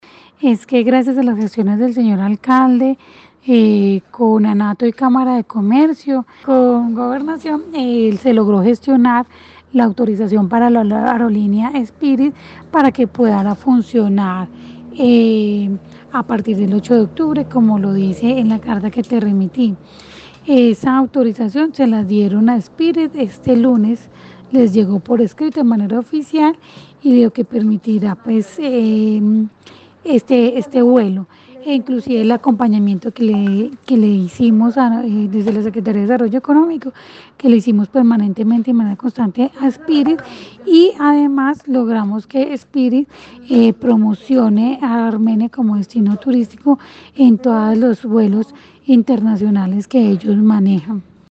Audio: Margarita María Ramírez, Sec. de Desarrollo Económico